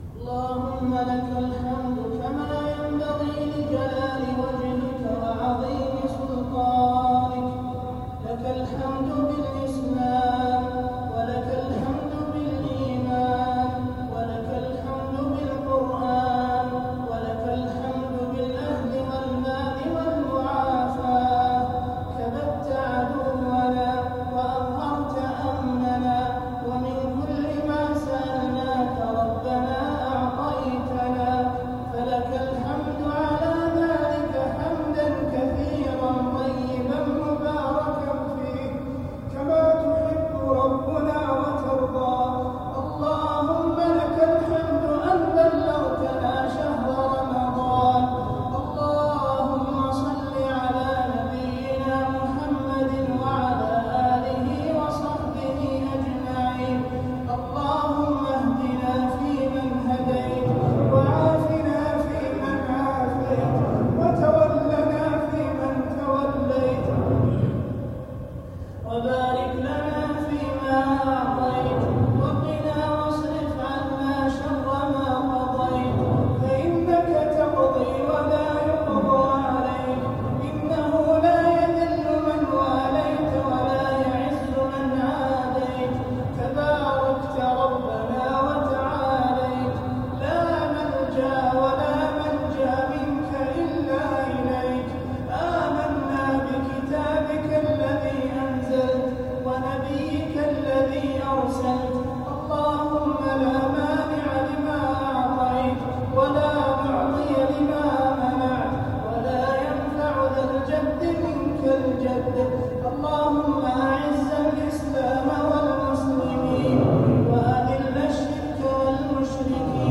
دعاء الليلة الأولى من ليالي رمضان ١٤٤٧هـ للقارئ